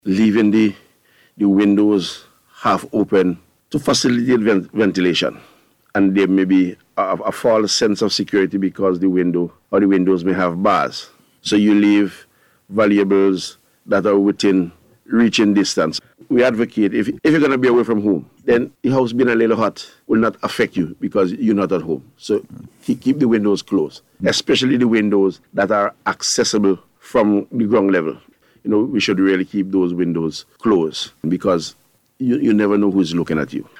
Speaking on NBC’s Face to Face Program on Monday, ACP Bailey emphasized the importance of being vigilant during the holiday season.